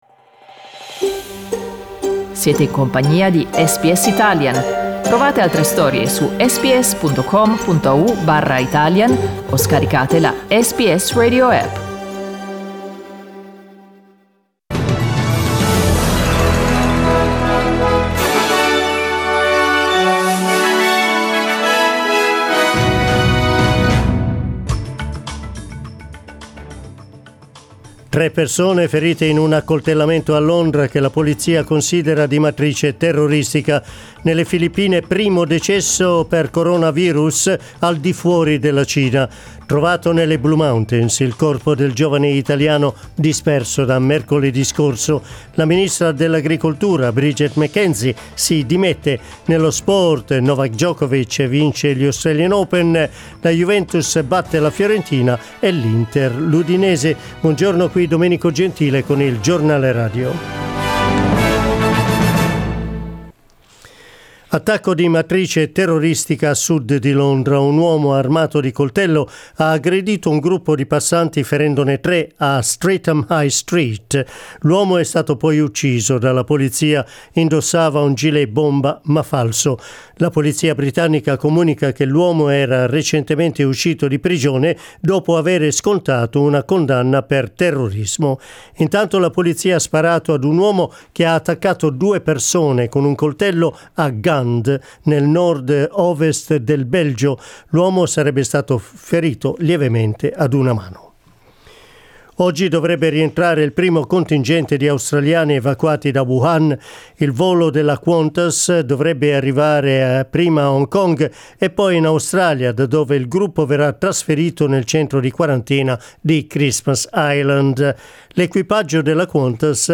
Giornale radio lunedì 3 febbraio